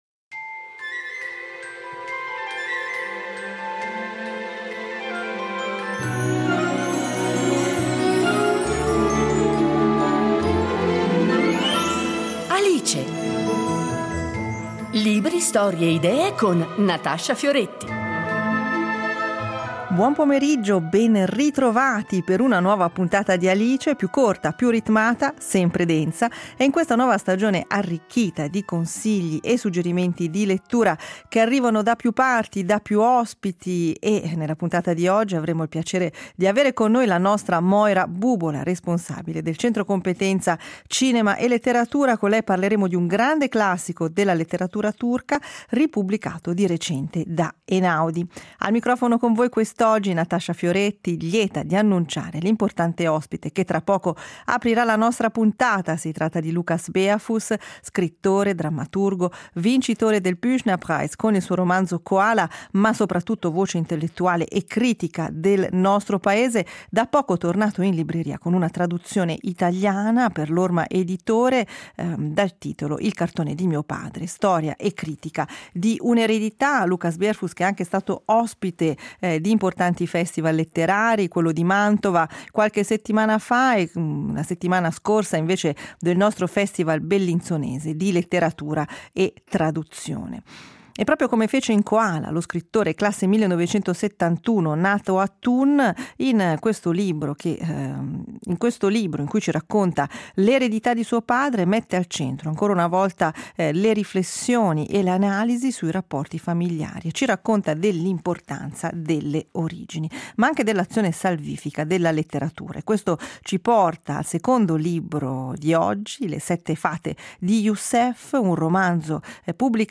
Intervista a Lukas Bärfuss